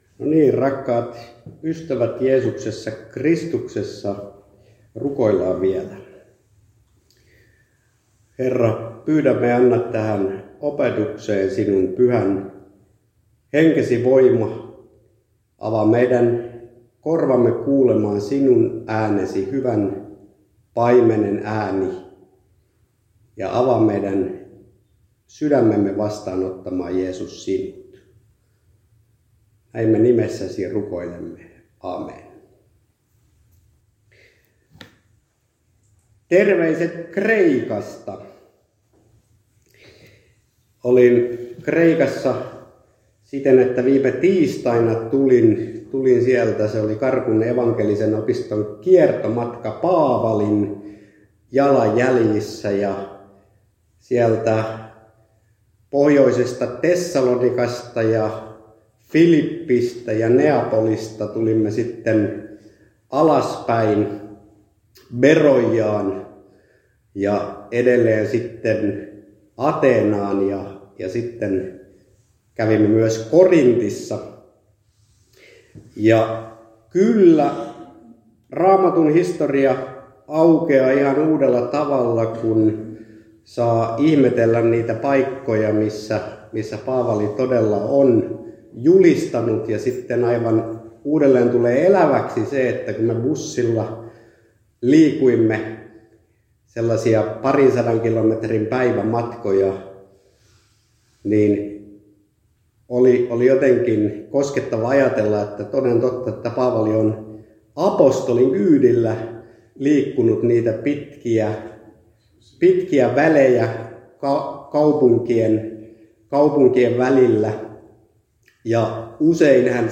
Alajärvellä Siionin kannel -lauluhetkessäPohjana room. 8